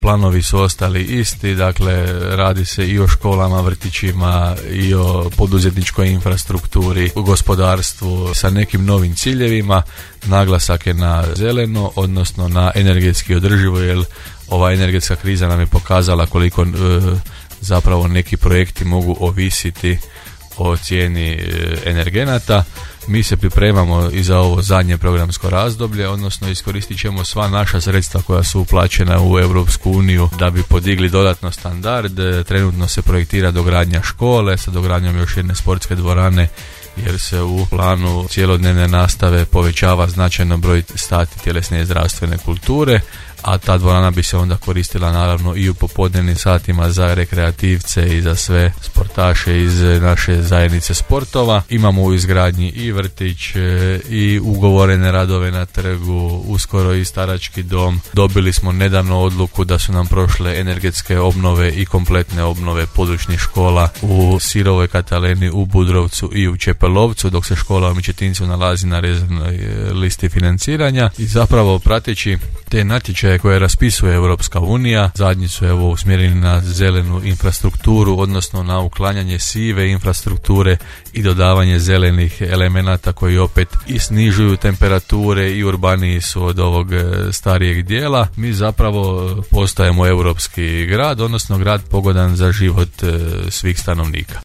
– rekao je gradonačelnik Grada Đurđevca Hrvoje Janči u emisiji Gradske teme.